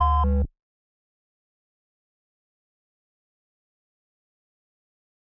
tudum_tresgrave.wav